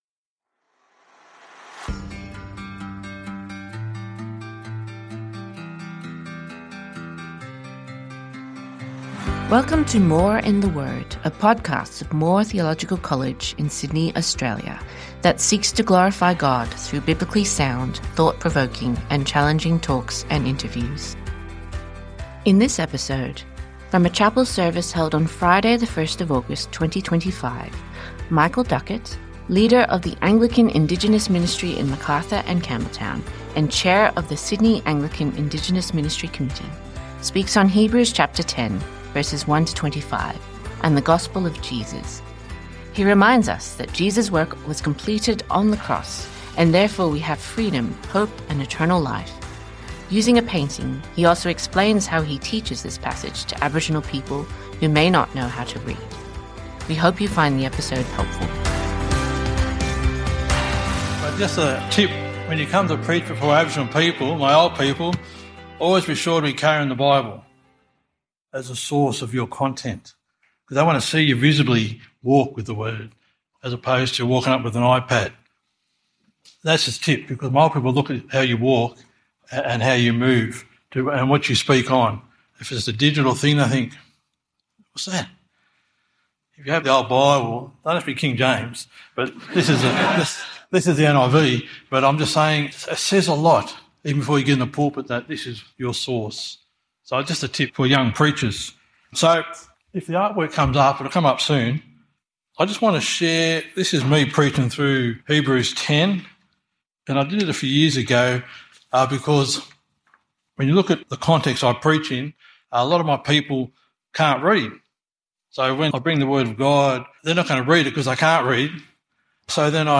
Biblically sound, thought-provoking and challenging talks and interviews from Moore Theological College.